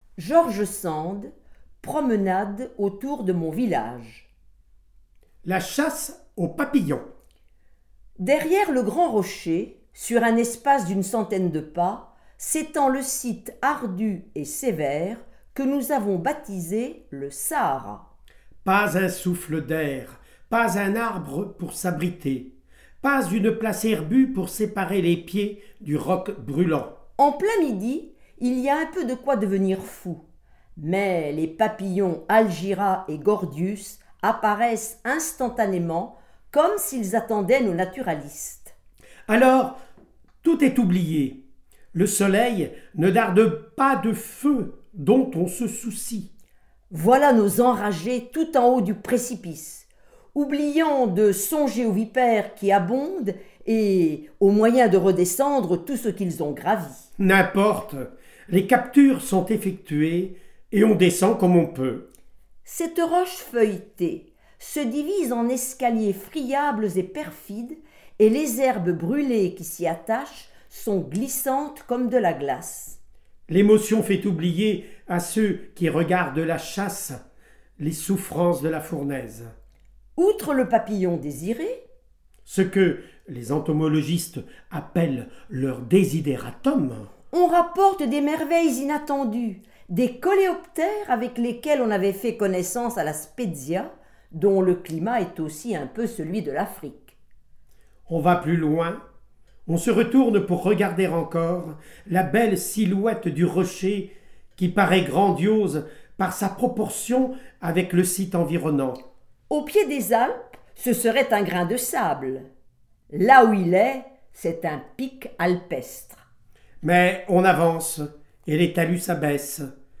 • Lectures des textes de George Sand sur les Sciences, réalisées à l’occasion du colloque George Sand et les sciences et vie de la terre et de l’exposition au Muséum d’histoire naturelle de Bourges